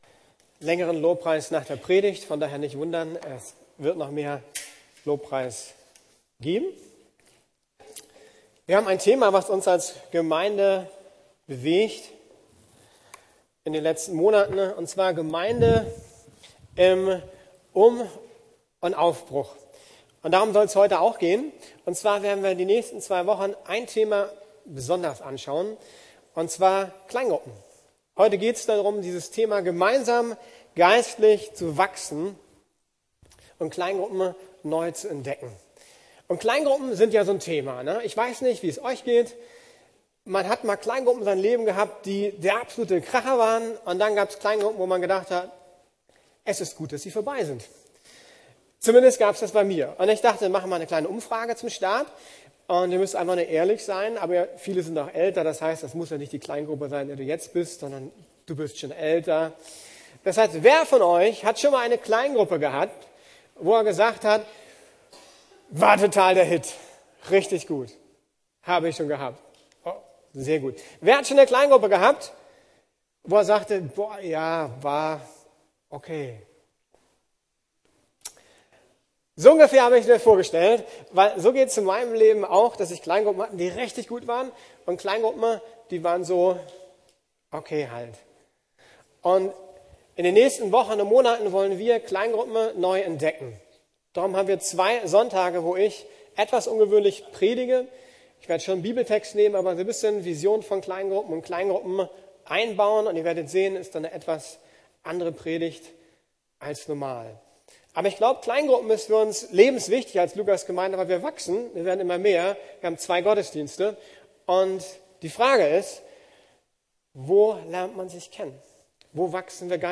Gemeinsam geistlich wachsen ~ Predigten der LUKAS GEMEINDE Podcast